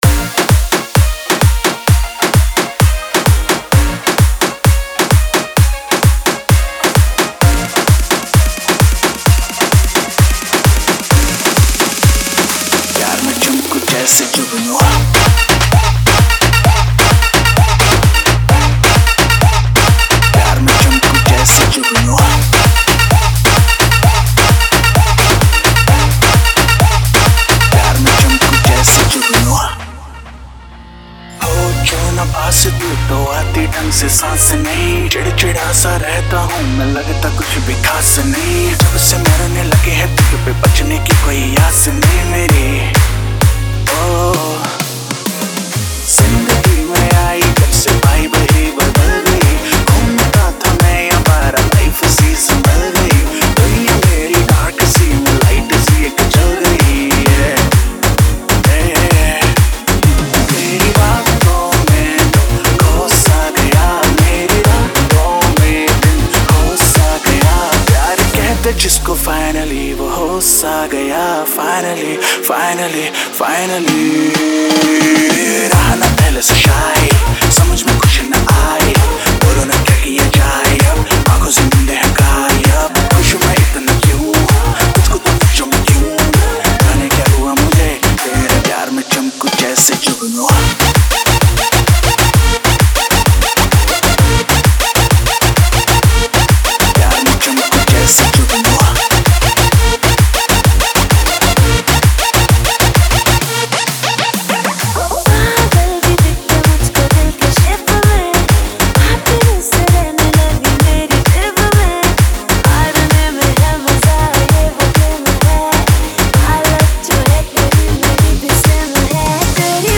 Singer : New DJ Remix